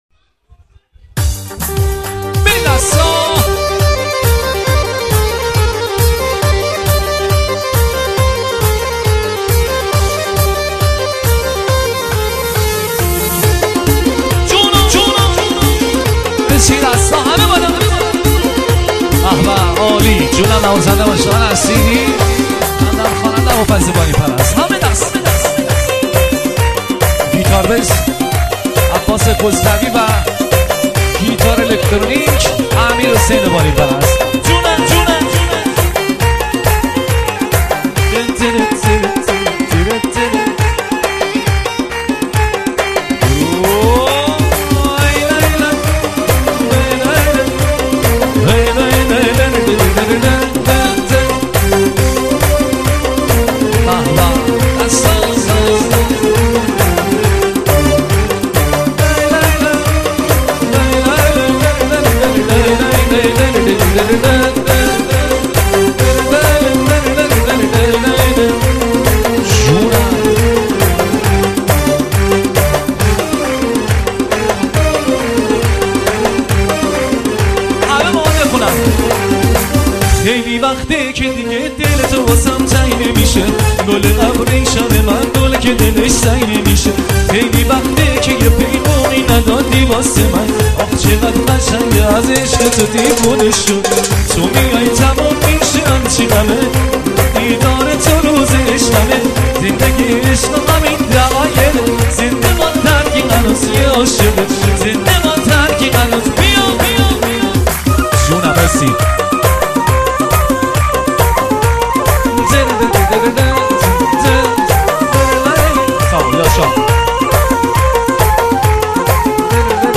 ارکستی